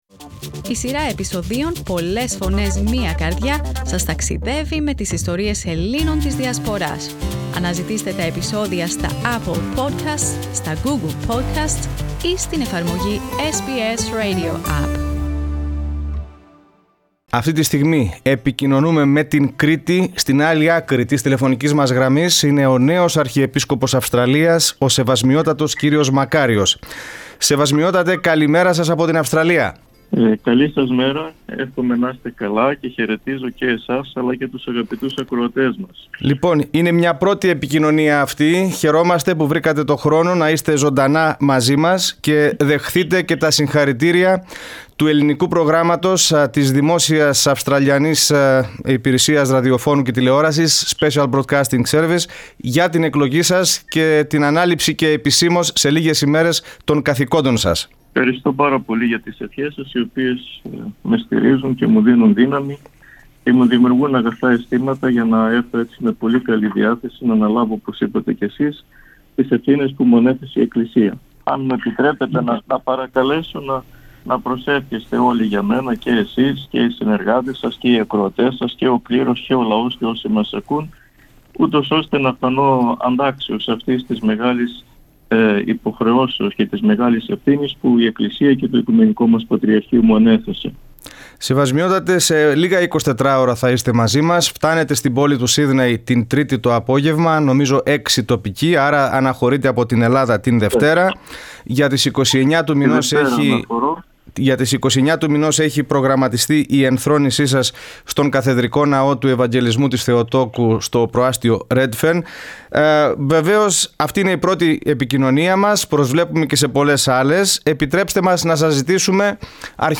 Εν όψει της άφιξής του στο Σύδνεϋ την ερχόμενη Τρίτη κο νέος Αρχιεπίσκοπος Αυστραλίας, κ. Μακάριος μίλησε στο Ελληνικό Πρόγραμμα της Ραδιοφωνίας SBS.